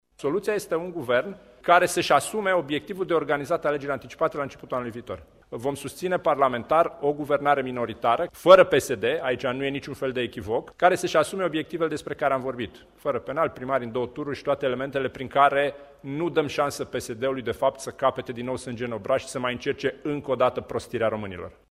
Preşedintele USR, Dan Barna, a anunţat că a înaintat Pactul pentru organizarea de alegeri anticipate.Potrivit acestuia, şeful statului a fost ‘cumva’ în asentimentul reprezentanţilor USR în ceea ce priveşte oportunitatea convocării alegerilor anticipate: